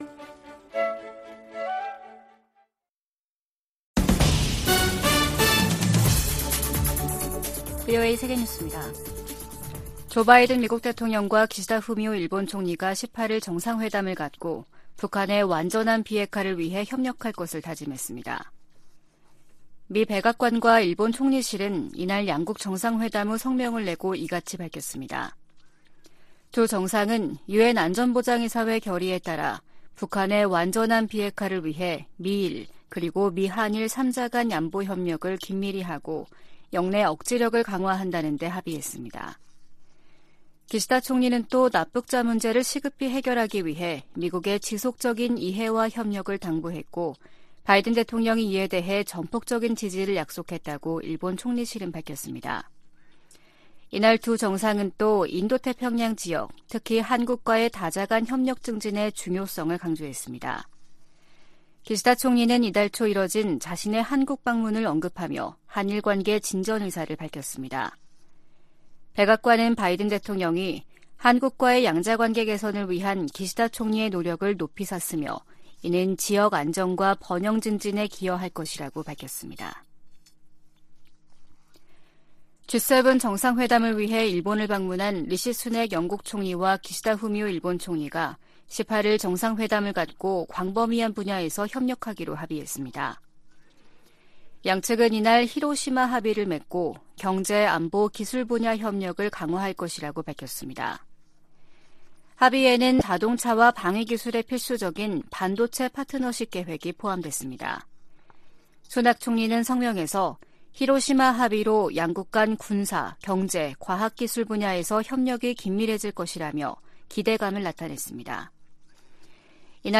VOA 한국어 아침 뉴스 프로그램 '워싱턴 뉴스 광장' 2023년 5월 19일 방송입니다. 일본 히로시마에서 열리는 주요 7개국(G7) 정상회의 기간에 미한일 정상회담을 추진 중이라고 미국 백악관 고위 당국자가 밝혔습니다. 미 국무부 북한인권특사 지명자는 유엔 안보리의 북한 인권 공개회의를 재개하고 인권 유린에 대한 책임을 묻겠다고 밝혔습니다. 윤석열 한국 대통령이 주요국 정상들과 잇따라 회담을 갖는 '외교 슈퍼위크'가 시작됐습니다.